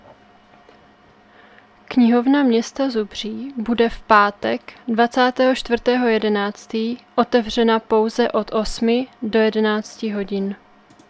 Zařazení: Rozhlas